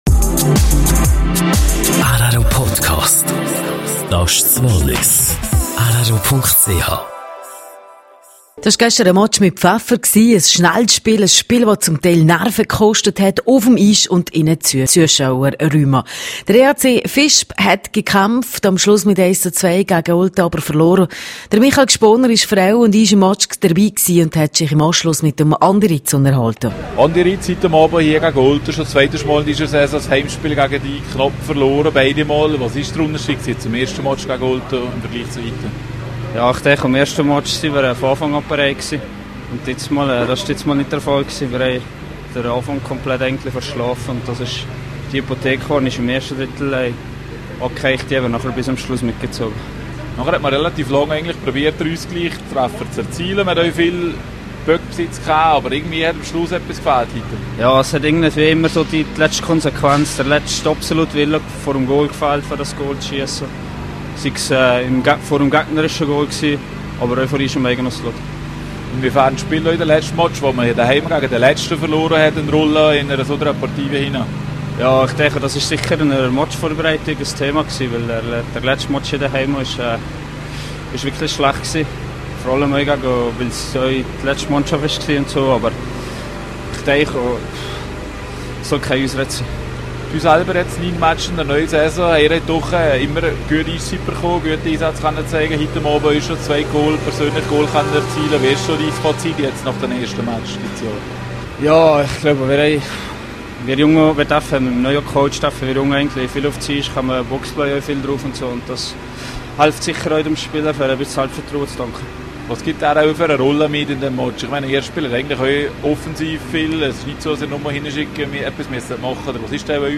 EHC Visp - EHC Olten: Die treuen Fans des EHC. Interview